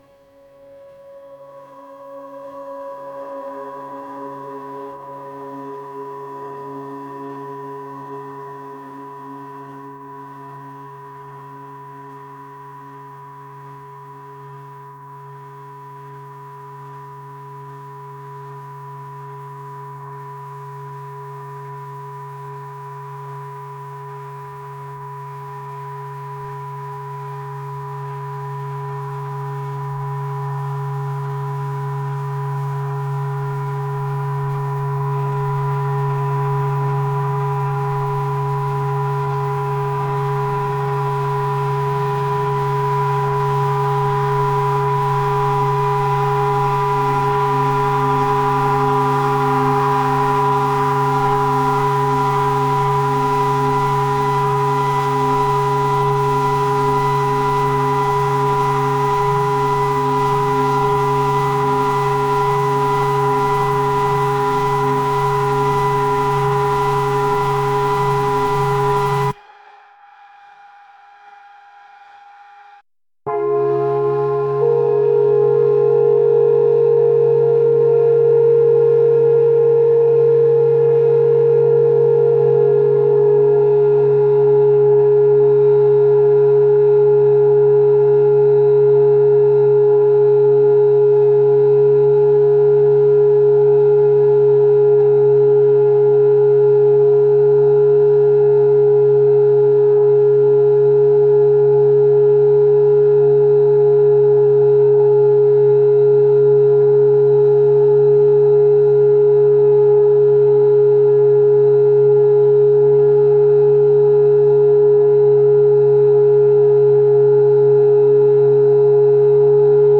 atmospheric | rock